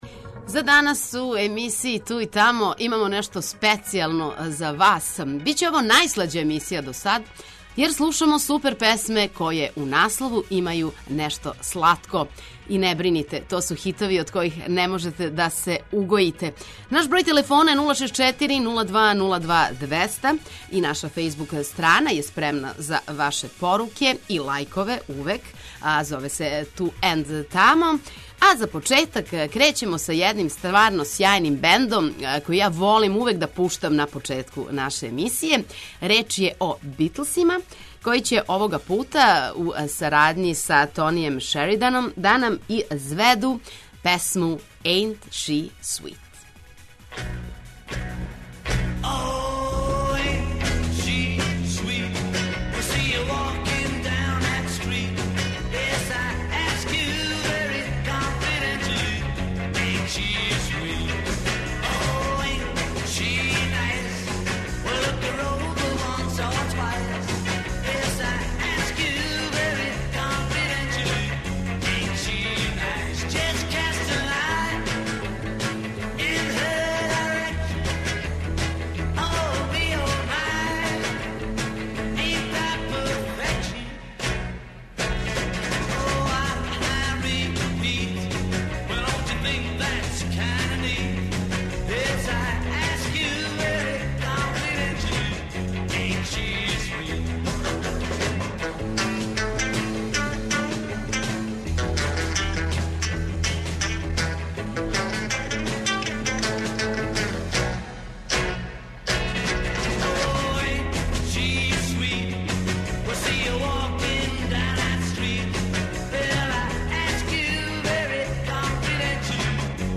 На програму су супер песме које у насловима имају нешто слатко или 'свит'.
Очекују вас велики хитови, страни и домаћи, стари и нови, супер сарадње, песме из филмова, дуети и још много тога.